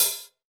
PFOOT HH.wav